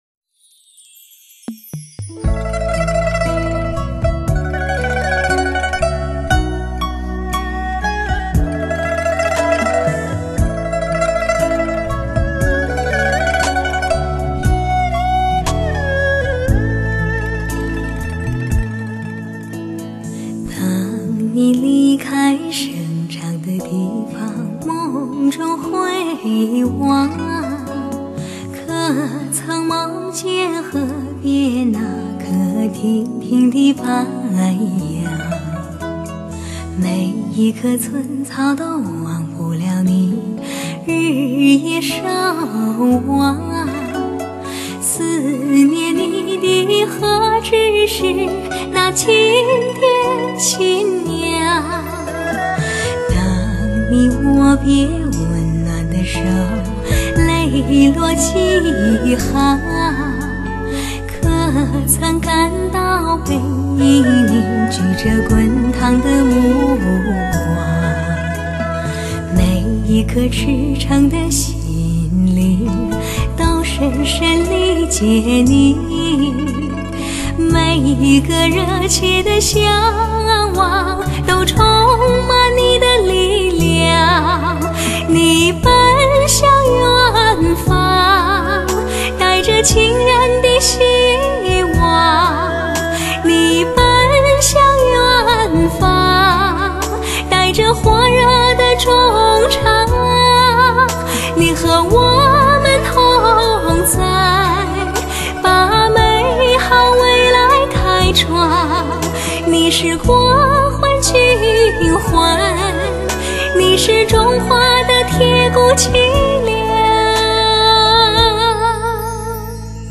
无敌的HIFI录音及音乐聆听，竟如中毒般让人折服并为之倾倒，音响发烧友苦
苦寻觅之人声和乐器质感，动态，定位，空气感等等要素全盘奉上！